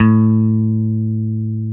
Index of /90_sSampleCDs/Roland LCDP02 Guitar and Bass/GTR_Dan Electro/GTR_Dan-O Guitar